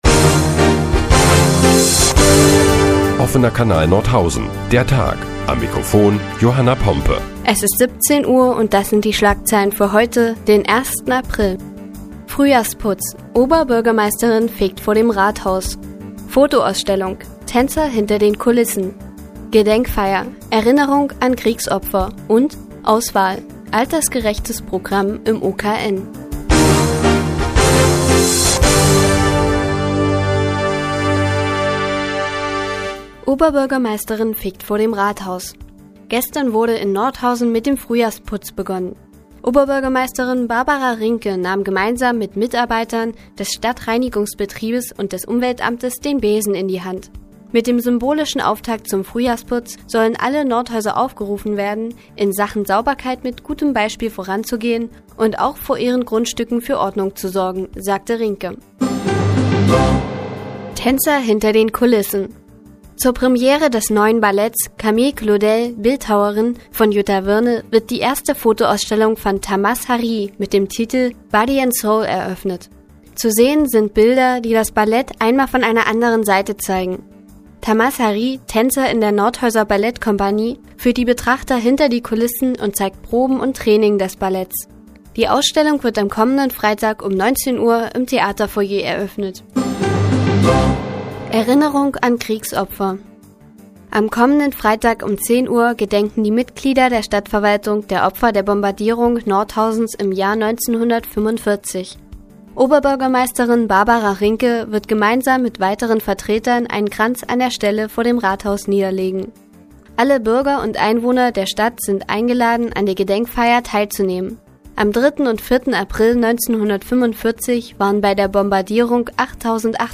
Die tägliche Nachrichtensendung des OKN ist nun auch in der nnz zu hören. Heute geht es unter anderem um eine fegende Oberbürgermeisterin und Tänzer hinter den Kulissen.